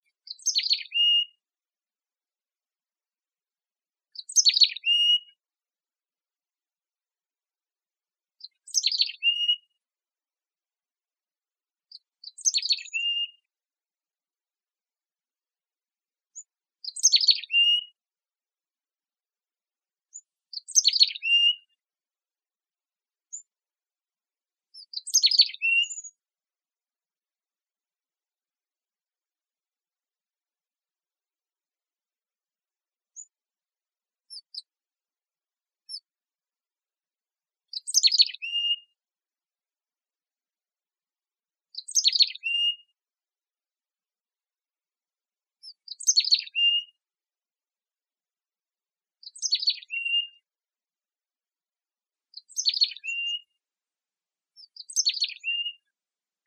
mountainchickadee.wav